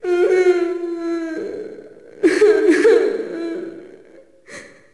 Index of /Downloadserver/sound/zp/zombie/female/